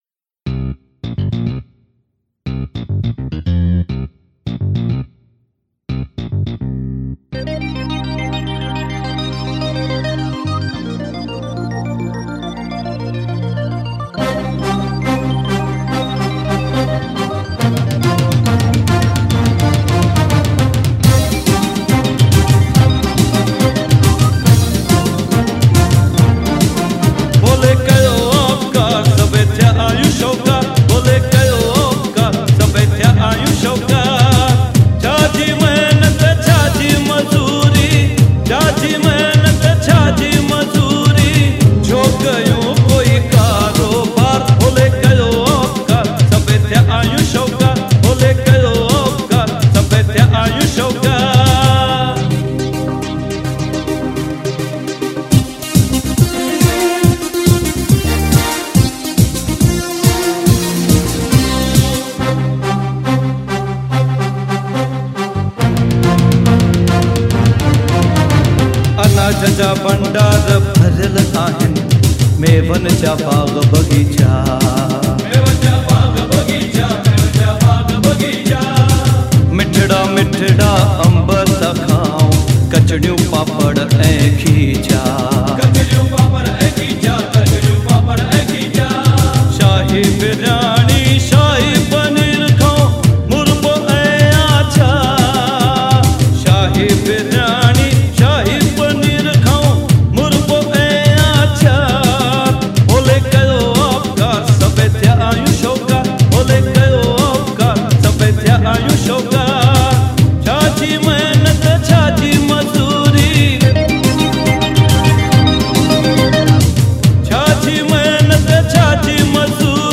New kind of Sindhi Songs